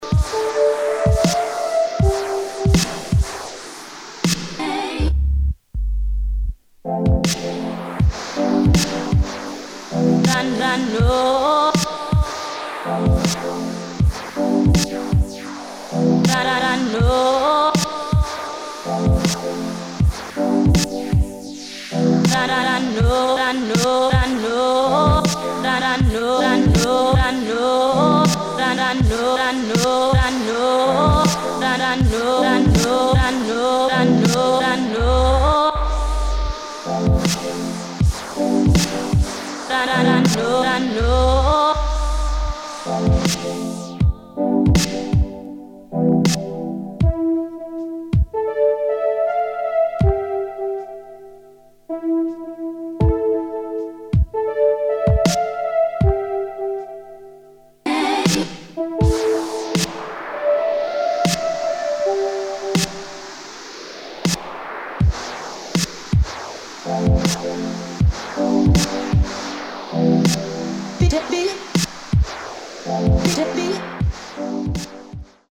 2. DUBSTEP | BASS
[ BASS ]